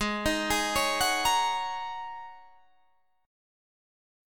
Ab7sus2sus4 Chord